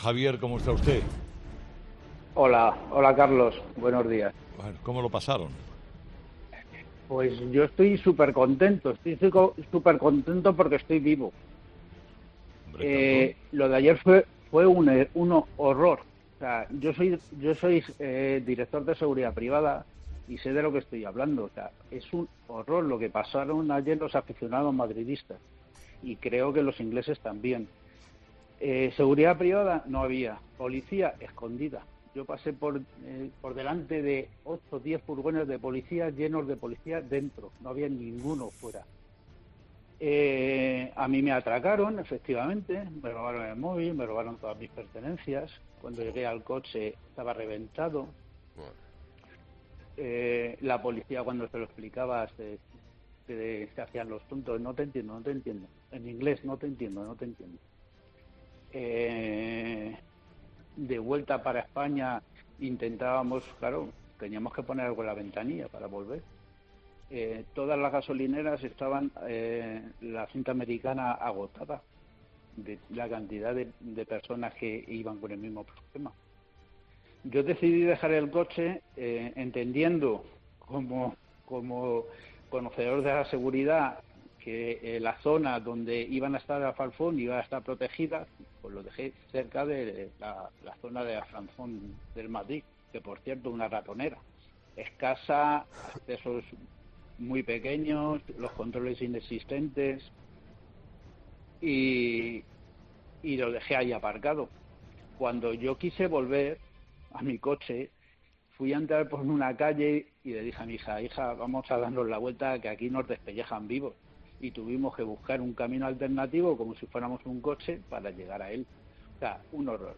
Un oyente de 'Herrera en COPE' ha relatado el "horror" que vivió con su hija tras la final de la Champions